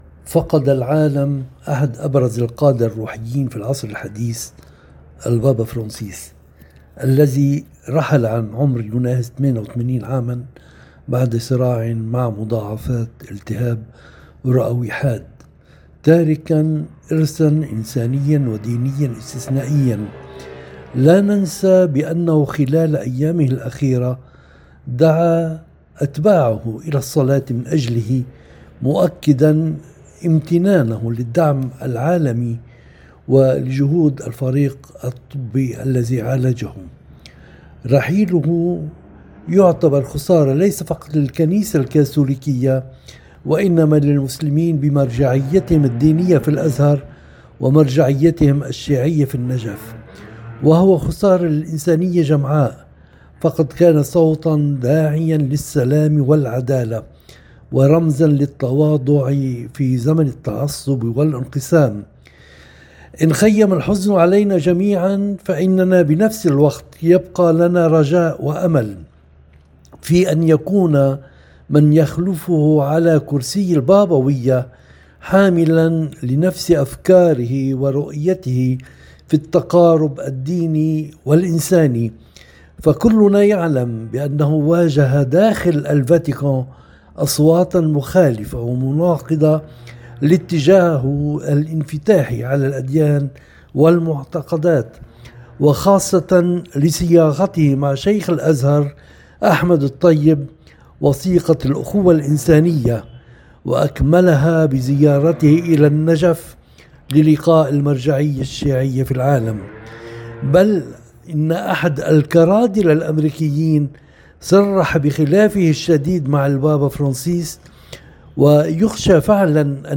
في حديثٍ يتناول جوانب بارزة من مسيرة البابا فرنسيس، من إصلاحاته الجريئة داخل الفاتيكان ومحاربته للفساد، إلى دوره المحوري في ترسيخ الحوار بين الأديان، وعلاقته المتينة بمؤسسة الأزهر، والإرث الإنساني الذي خلّفه كصوت داعم للسلام العالمي… تسلط هذه المقابلة الضوء أيضًا على التحديات التي واجهها البابا داخل الفاتيكان، لا سيما من أصوات كانت ترفض التقارب بين الإسلام والمسيحية.